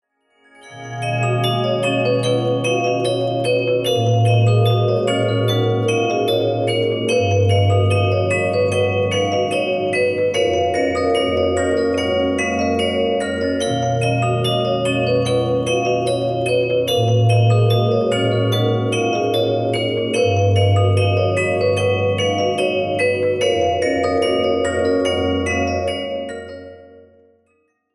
• Качество: 256, Stereo
спокойные
волшебные
колокольчики
Музыкальная шкатулка